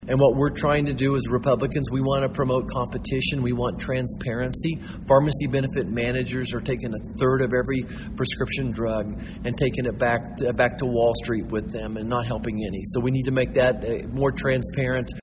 Touting a message of optimism for Kansas farmers, 1st District Congressman and current Republican Senate candidate Roger Marshall spoke to a gathering at the annual Young Farmers & Ranchers Leaders Conference Saturday at the Manhattan Conference Center.